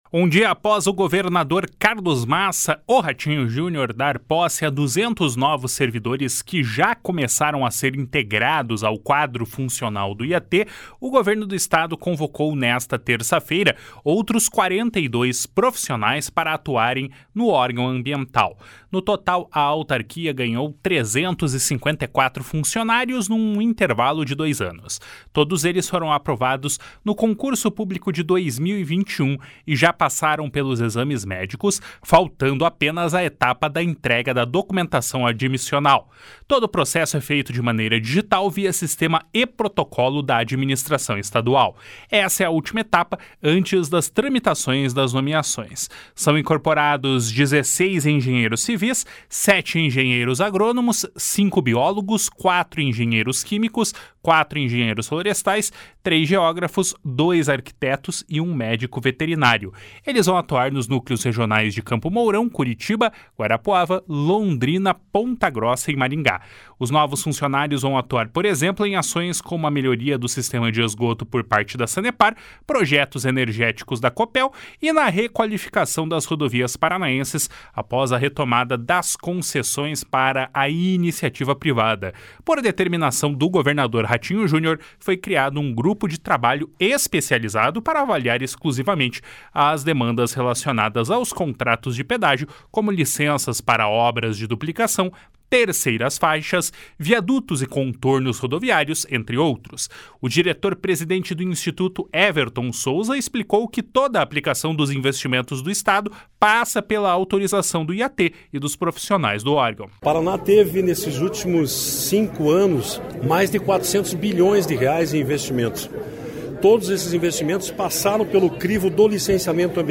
// SONORA EVERTON SOUZA //